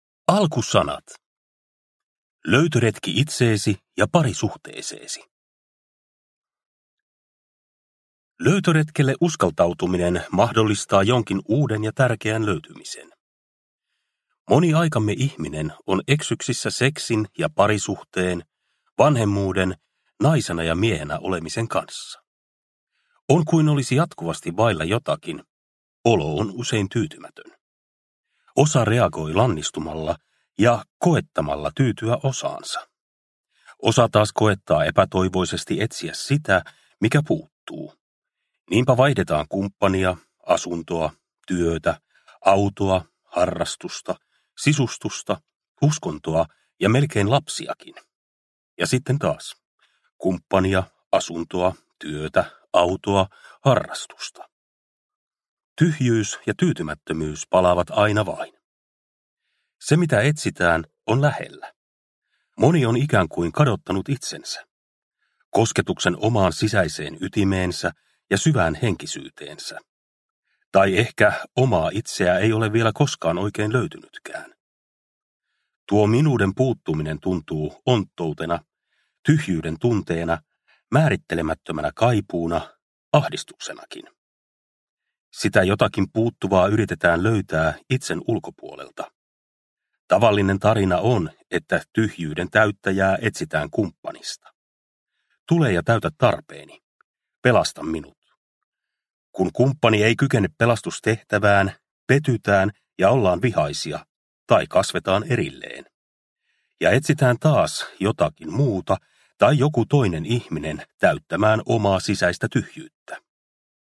Pari suhteessa – Ljudbok – Laddas ner